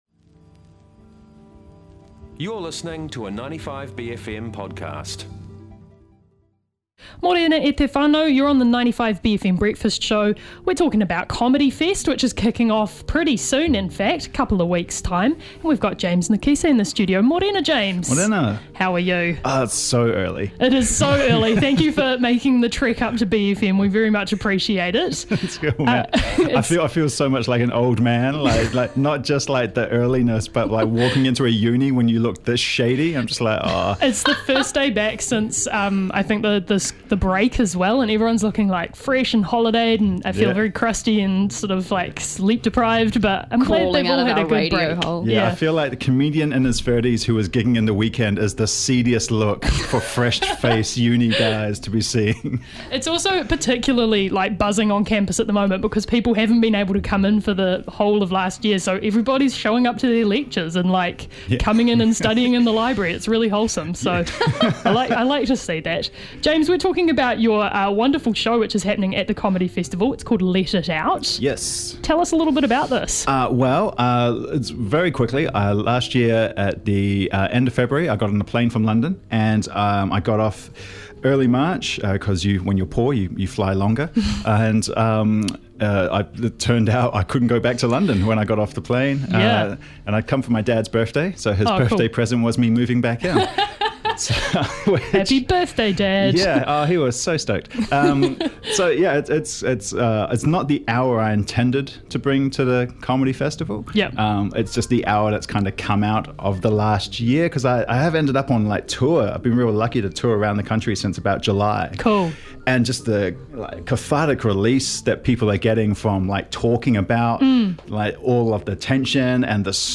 popped in to studio